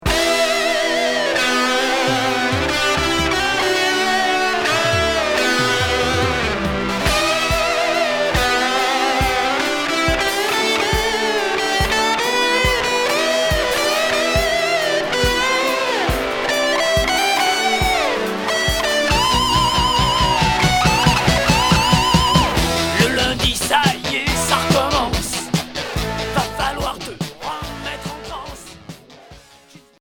Rock Deuxième 45t retour à l'accueil